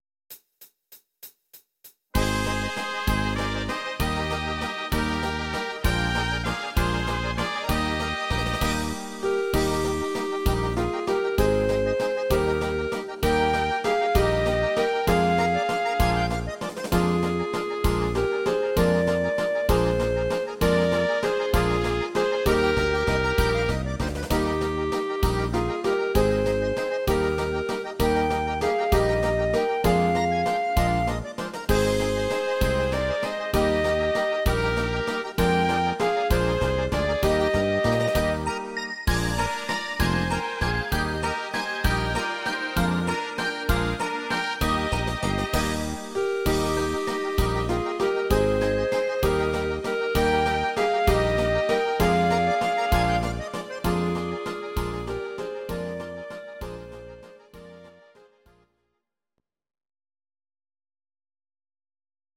Audio Recordings based on Midi-files
German, Duets, Volkst�mlich